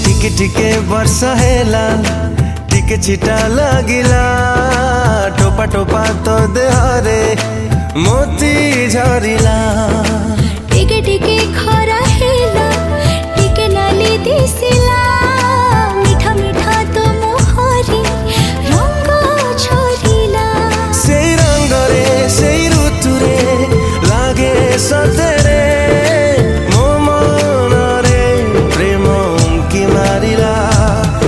Odia Ringtones
love song